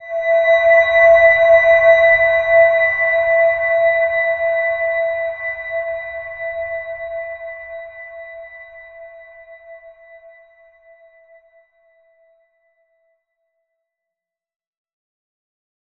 Silver-Gem-E5-f.wav